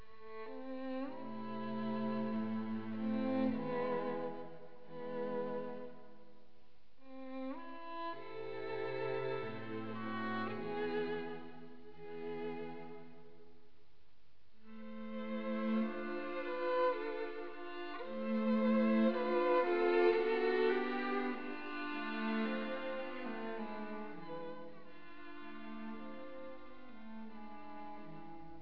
String Trios